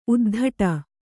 ♪ uddhaṭa